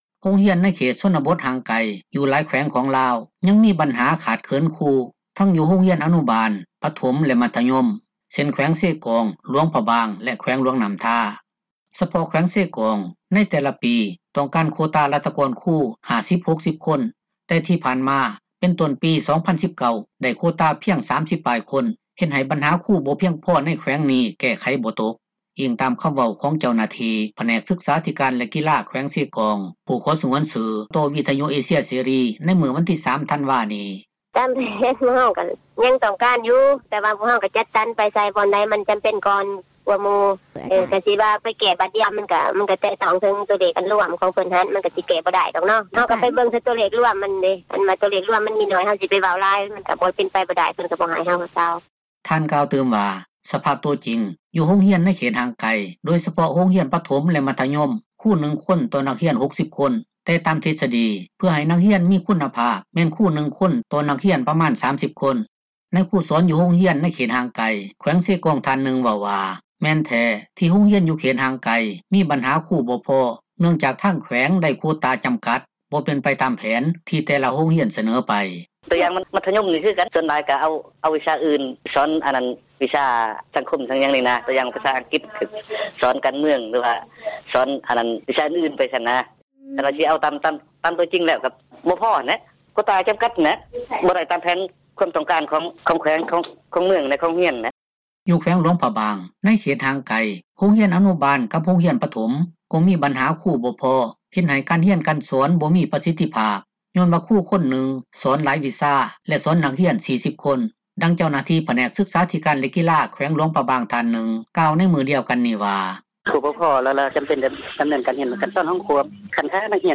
ໂຮງຮຽນເຂດຊົນນະບົດ ຍັງຂາດຄຣູ — ຂ່າວລາວ ວິທຍຸເອເຊັຽເສຣີ ພາສາລາວ